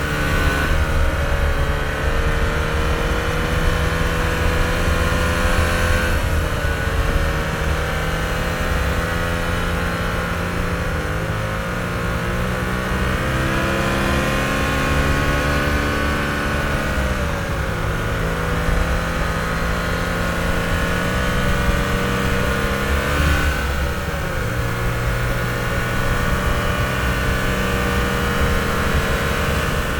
car-accel-sfx.mp3